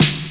• Sharp Snare Drum Sound D Key 135.wav
Royality free steel snare drum sample tuned to the D note. Loudest frequency: 2388Hz
sharp-snare-drum-sound-d-key-135-vd7.wav